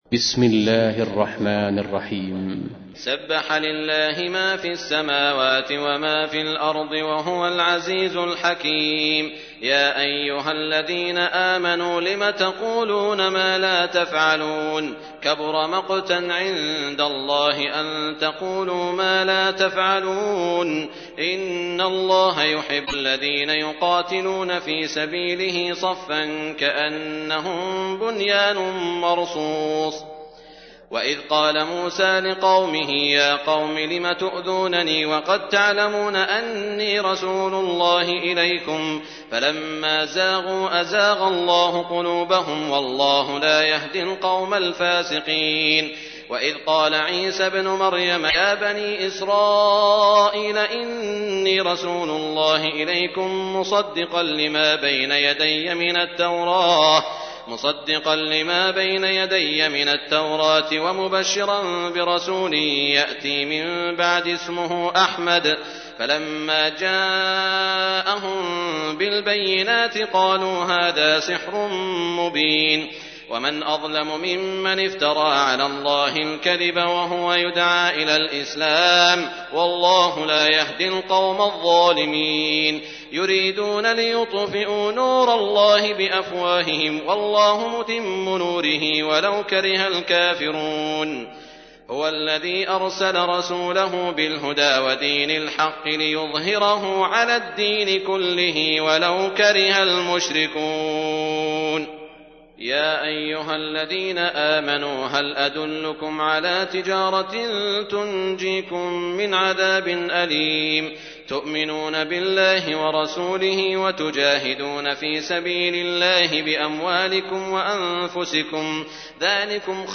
تحميل : 61. سورة الصف / القارئ سعود الشريم / القرآن الكريم / موقع يا حسين